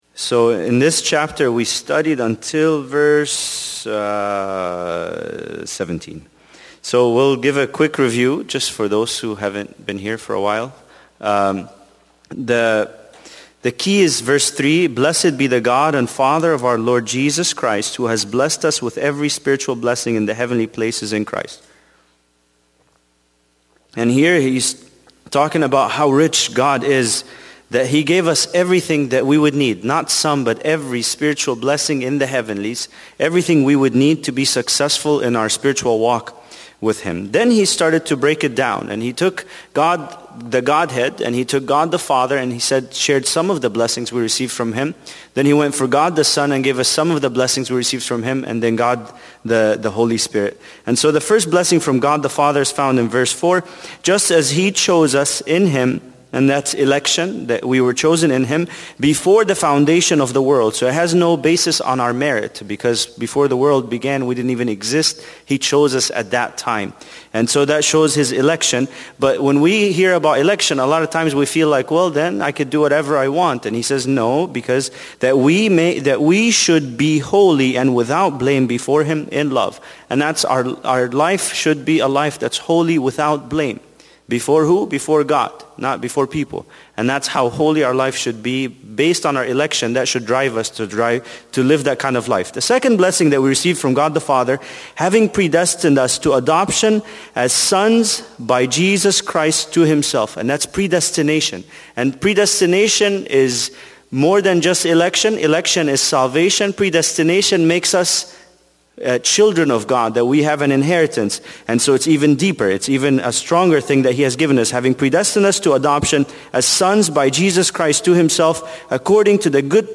Bible Study: Ephesians 1 part 7